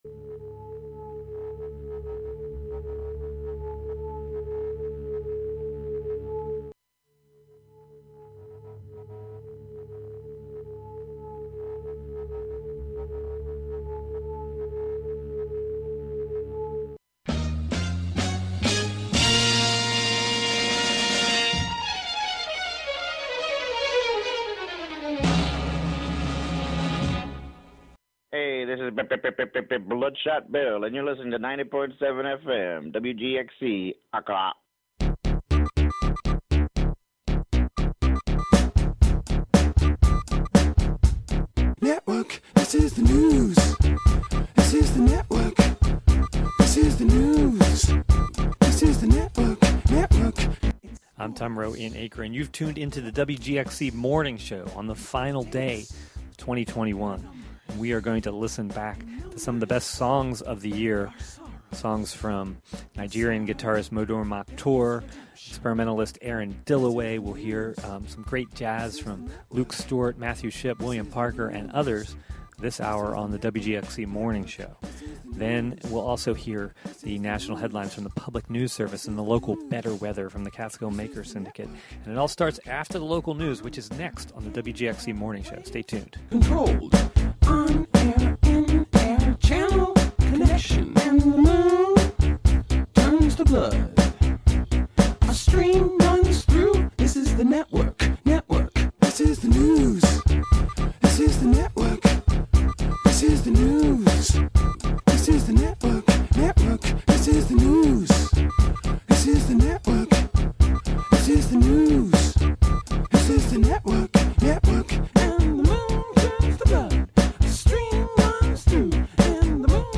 Today, some of the best songs of the year are featured
is a radio magazine show